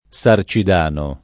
Sarcidano [ S ar © id # no ] top. m. (Sard.)